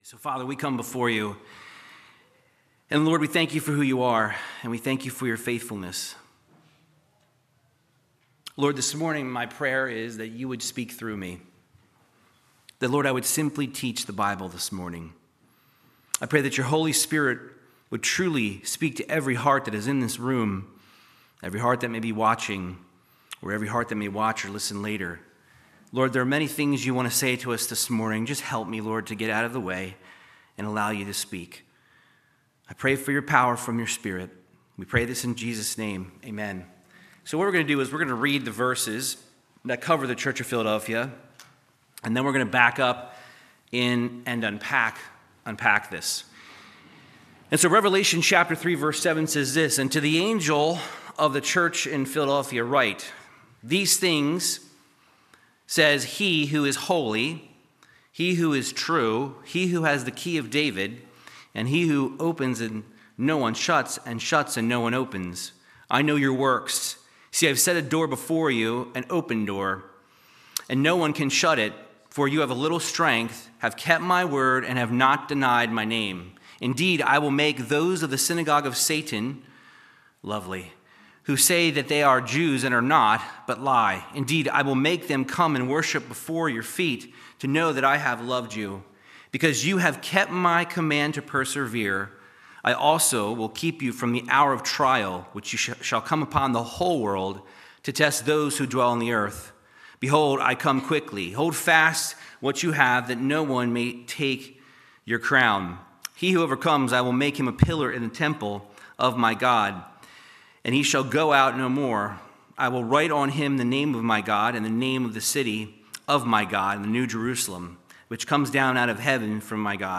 Verse by verse Bible teaching through the book of Revelation 3:7-8